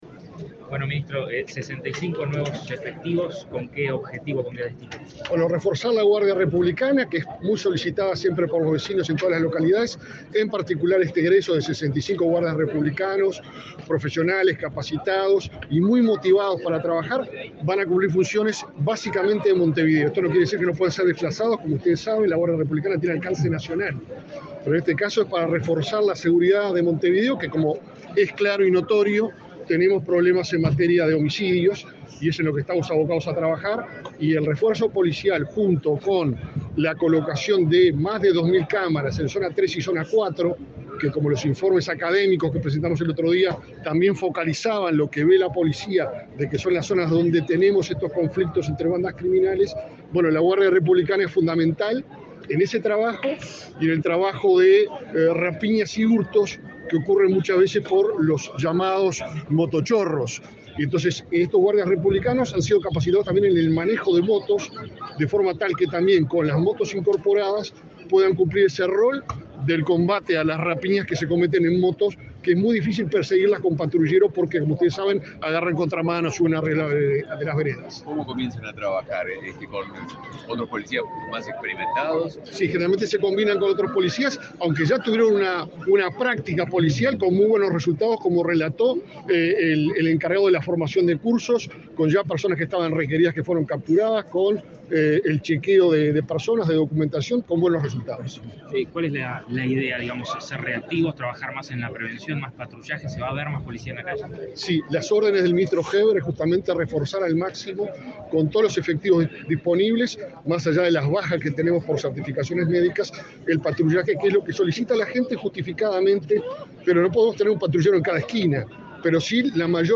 Declaraciones del ministro interino del Interior
El ministro interino del Interior, Guillermo Maciel, dialogó con la prensa luego de participar de la ceremonia de egreso de policías de Guardia